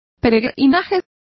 Also find out how peregrinaje is pronounced correctly.